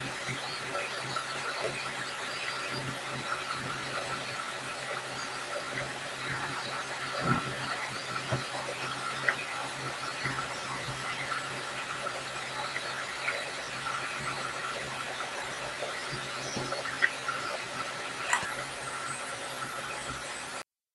amplified ultrasound recording chatter (only amplification)
ultrasound-chatter.mp3